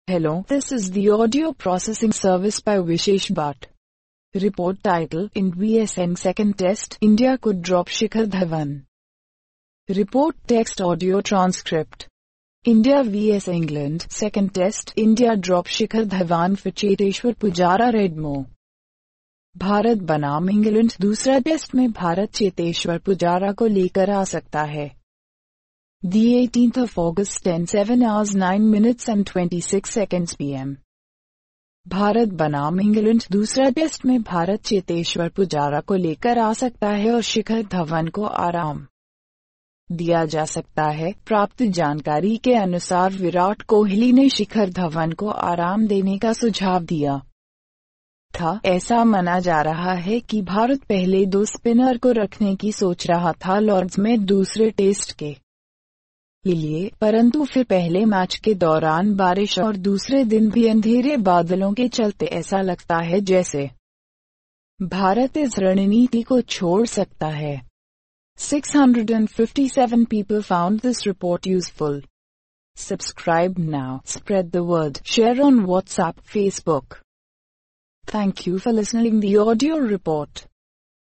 Audio Report of the Script – IND vs ENG 2nd Test: India could drop Shikhar Dhawan